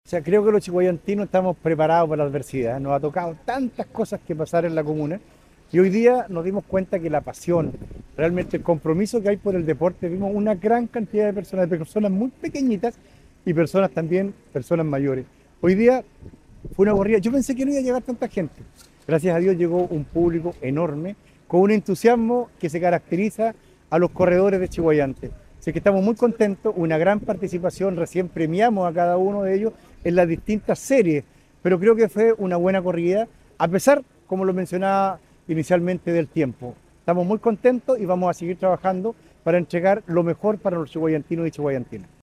El alcalde, Jorge Lozano, valoró el entusiasmo de los deportistas y agradeció la masiva participación, pues no esperaba tanta asistencia.